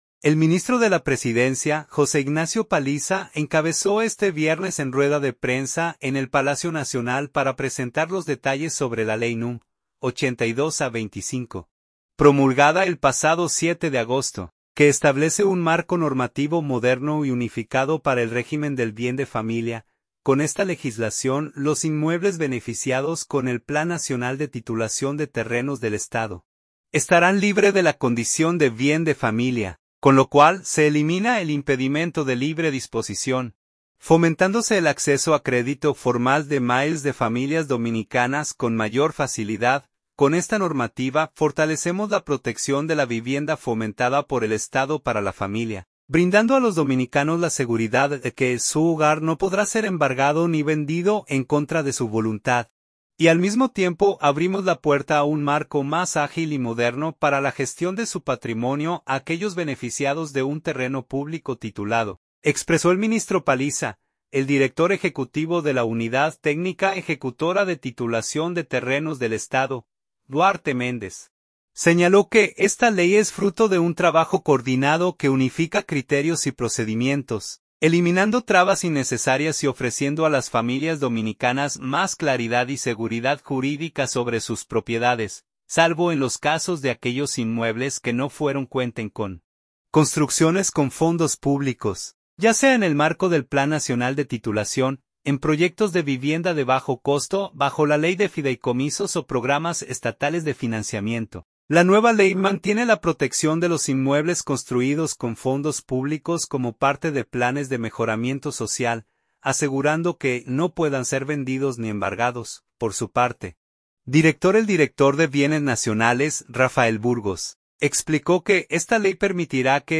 Santo Domingo.– El ministro de la Presidencia, José Ignacio Paliza, encabezó este viernes en rueda de prensa en el Palacio Nacional para presentar los detalles sobre la Ley núm. 82-25, promulgada el pasado 7 de agosto, que establece un marco normativo moderno y unificado para el régimen del Bien de Familia.